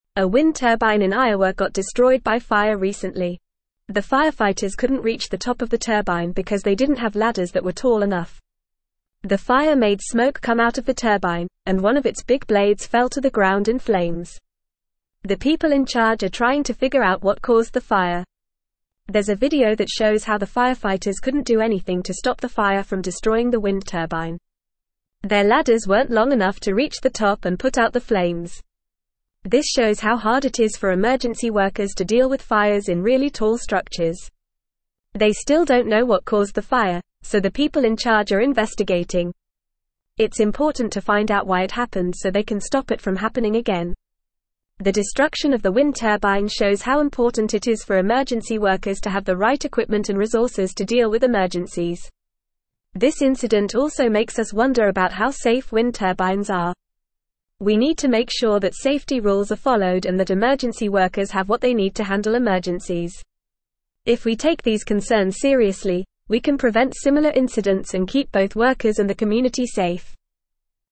Fast
English-Newsroom-Upper-Intermediate-FAST-Reading-Fire-Destroys-Wind-Turbine-Due-to-Lack-of-Equipment.mp3